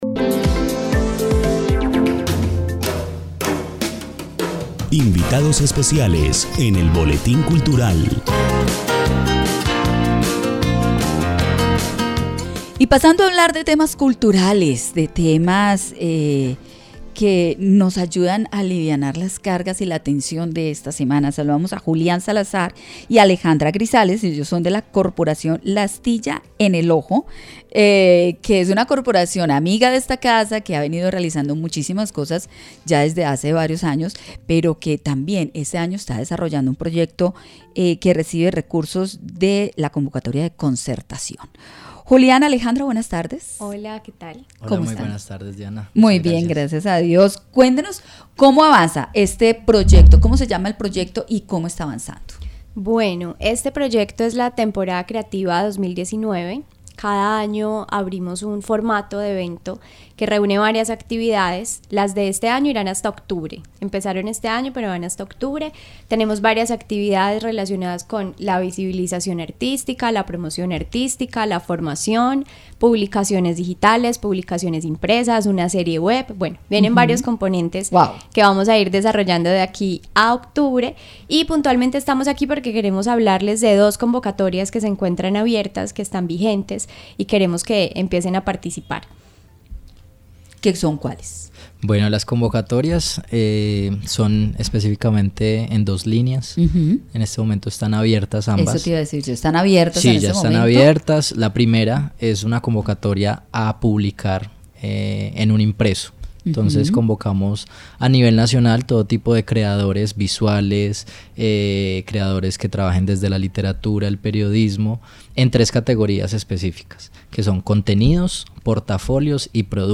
Visitamos la emisora cultural de Pereira y hablamos sobre las convocatorias vigentes. Compartimos con ustedes esta conversación: